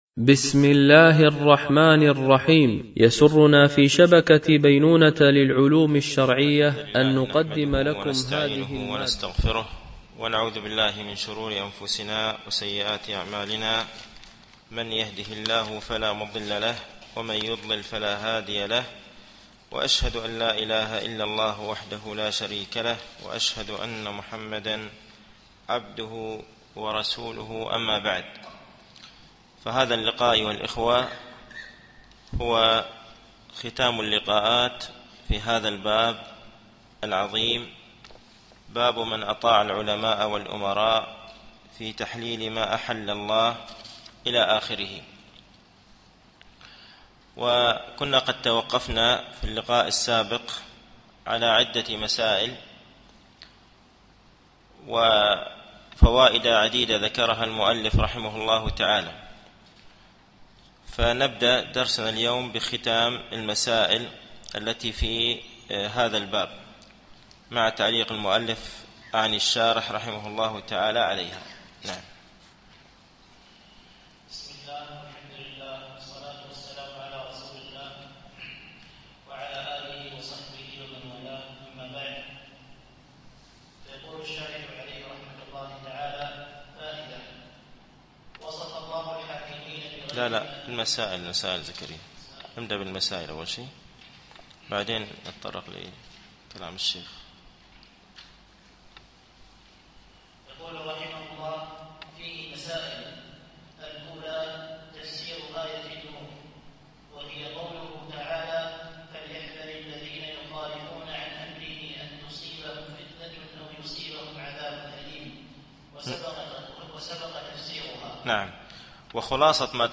التعليق على القول المفيد على كتاب التوحيد ـ الدرس الثاني و العشرون بعد المئة